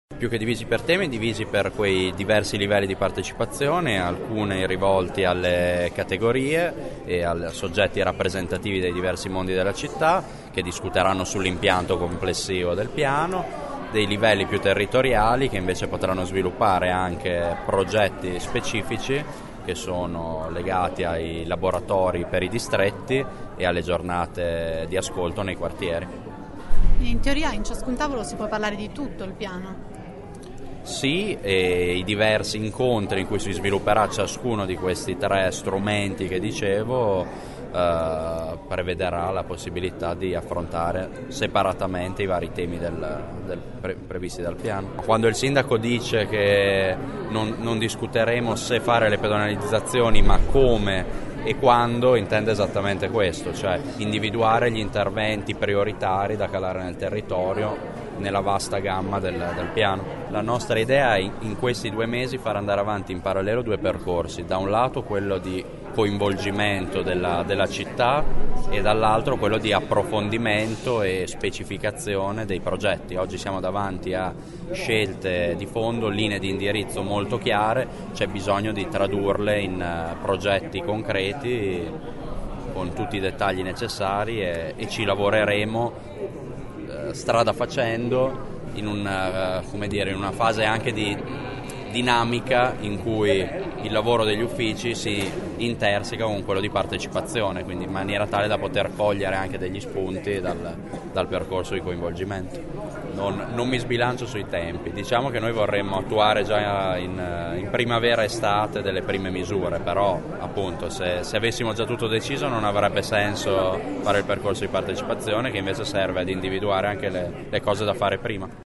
Ascolta l’assessore Colombo che parla della divisione dei tavoli,